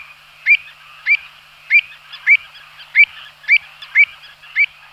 Avocette élégante
Recurvirostra avosetta
avocette.mp3